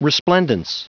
Prononciation du mot resplendence en anglais (fichier audio)
Prononciation du mot : resplendence